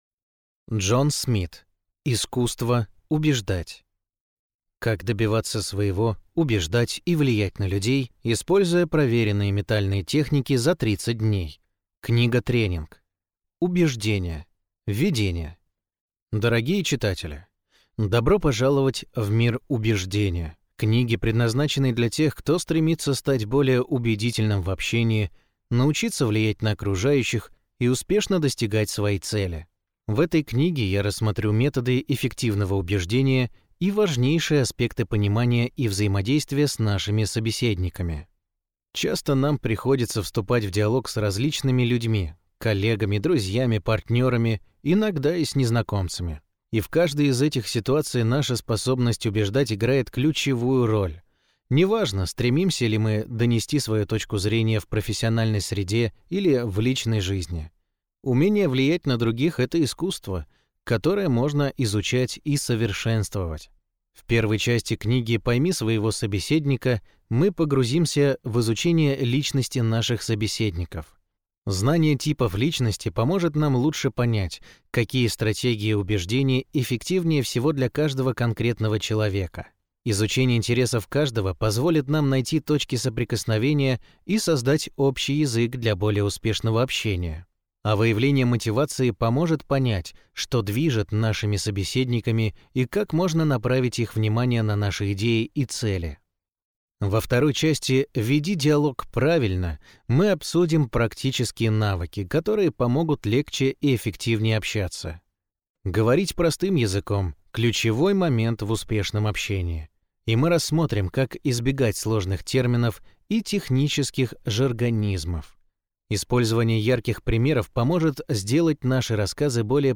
Аудиокнига Искусство убеждать.